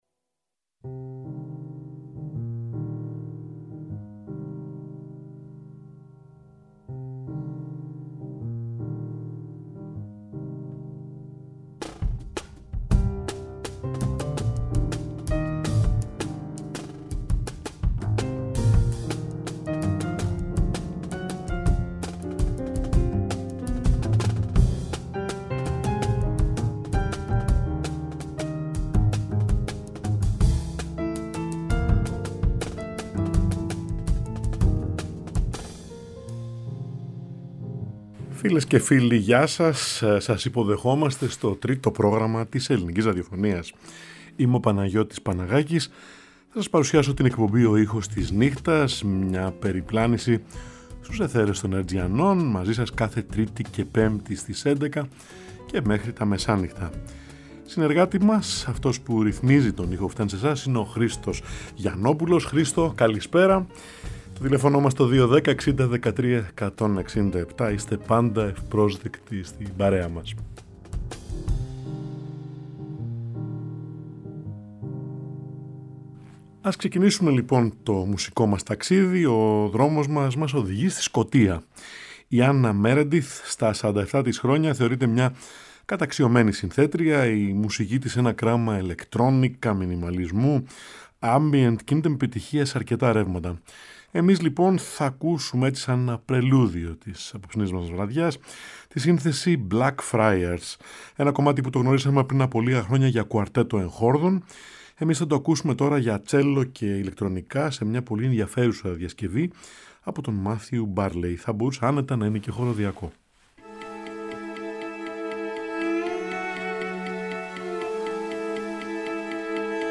Κάθε Τρίτη και Πέμπτη στις έντεκα, ζωντανά στο Τρίτο Πρόγραμμα